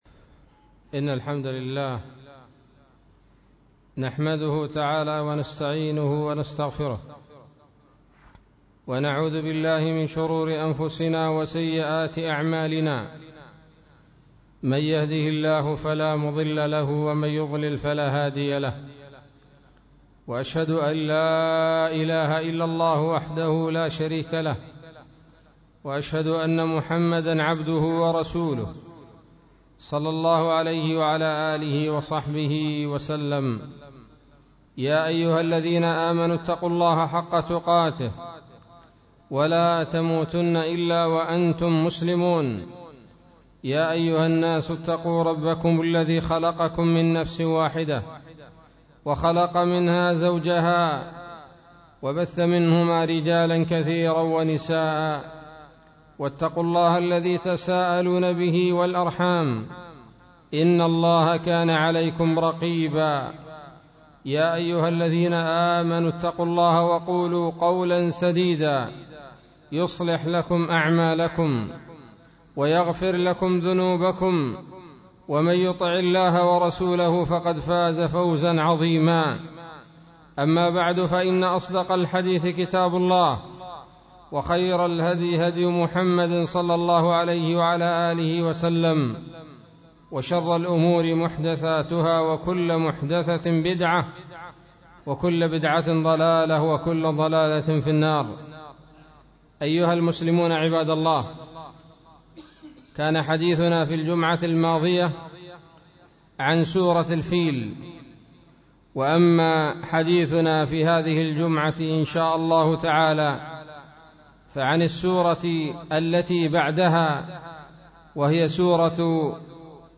خطبة بعنوان : ((تفسير سورة قريش)) 03 جمادي الاول 1437 هـ